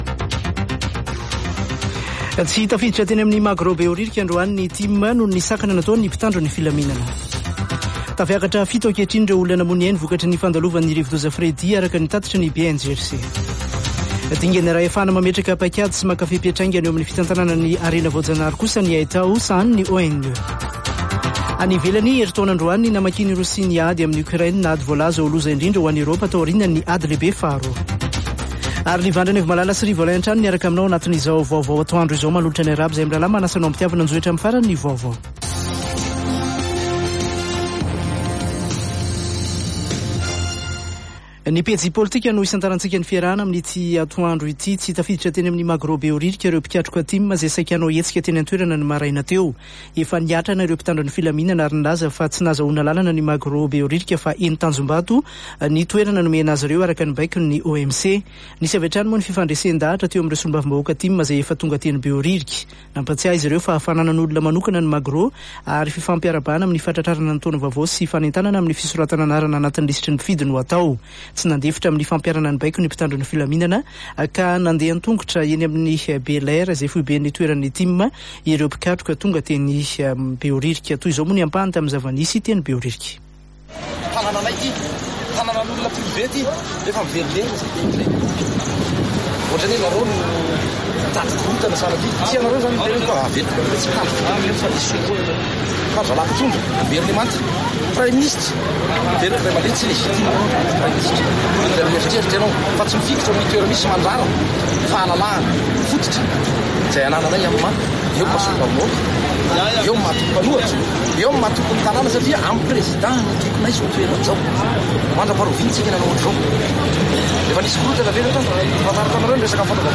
[Vaovao antoandro] Zoma 24 febroary 2023